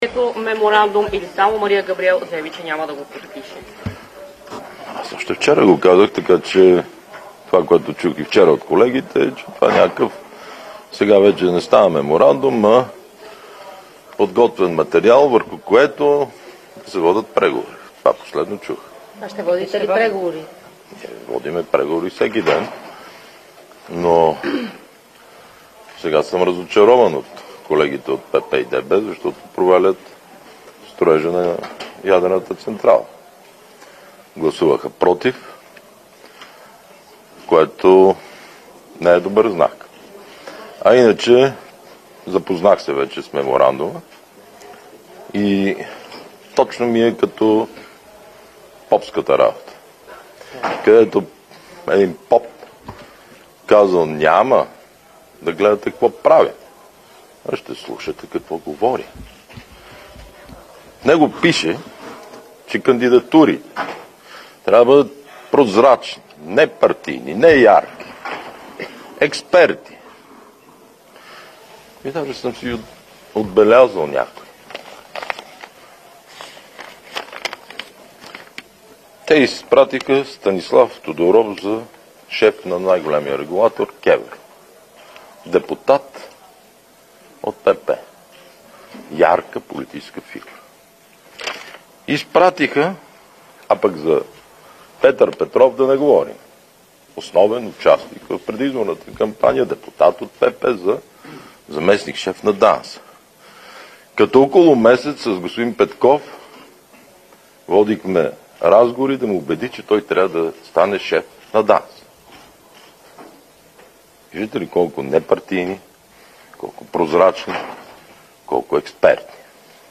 9.05 - Заседание на Народното събрание.
Директно от мястото на събитието